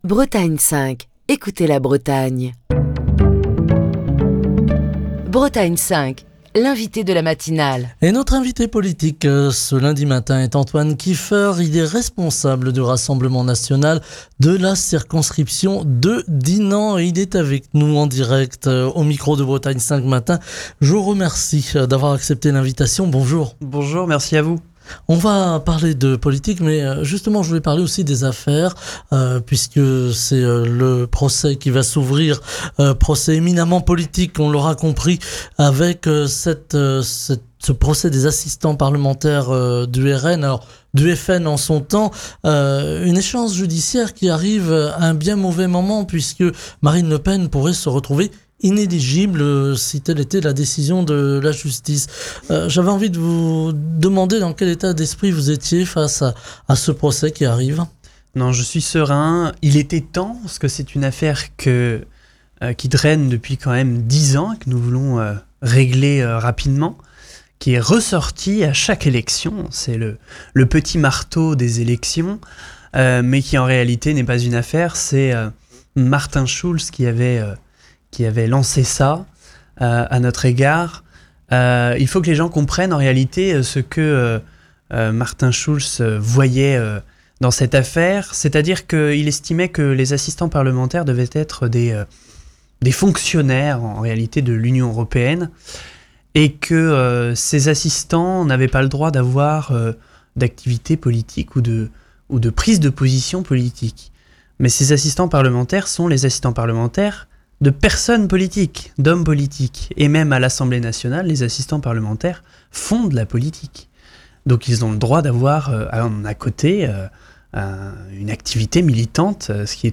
Émission du 30 septembre 2024.